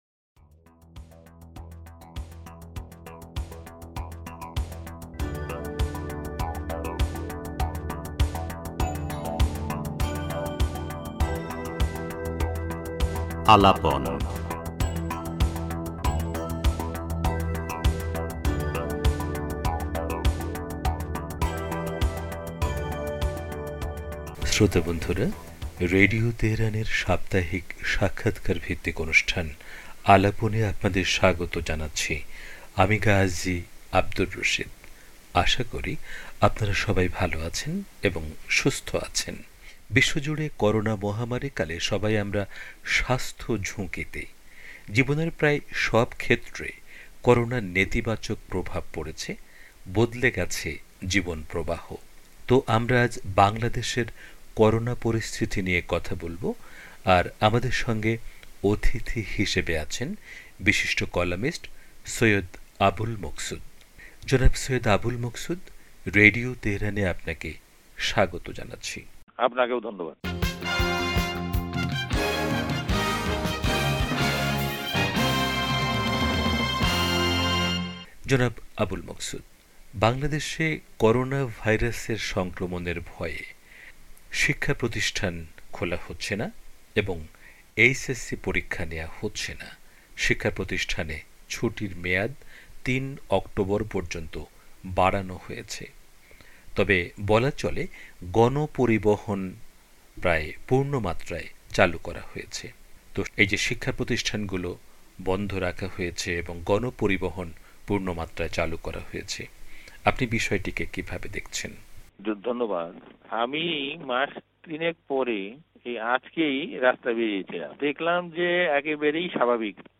রেডিও তেহরানকে দেয়া বিশেষ সাক্ষাৎকারে এসব কথা বলেন, বিশিষ্ট সাংবাদিক, কলামিস্ট ও গবেষক সৈয়দ আবুল মকসুদ। তিনি আরও বলেন, করোনা নিয়ে সরকারের কর্মকাণ্ডে মানুষ আস্থা রাখতে পারছে না।